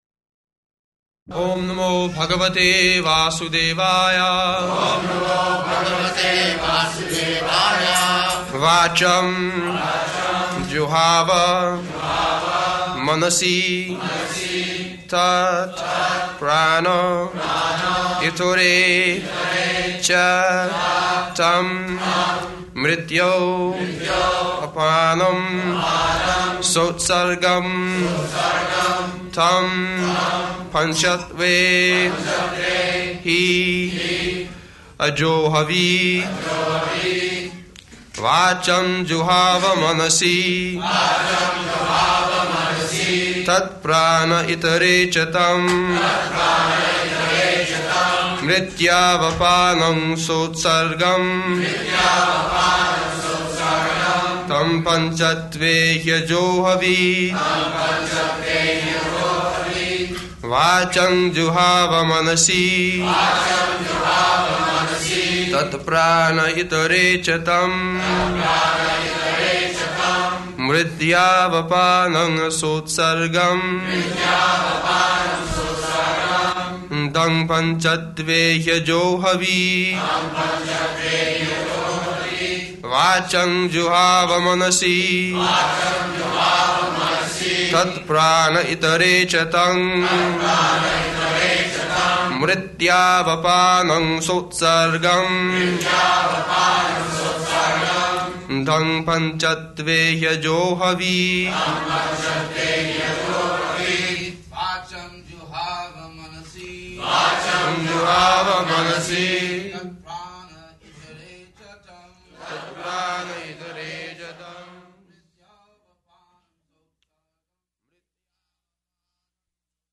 -- Type: Srimad-Bhagavatam Dated: December 19th 1973 Location: Los Angeles Audio file
[leads chanting of verse] [Prabhupāda and devotees repeat] vācaṁ juhāva manasi tat prāṇa itare ca tam mṛtyāv apānaṁ sotsargaṁ taṁ pañcatve hy ajohavīt [ SB 1.15.41 ] Prabhupāda: Yes?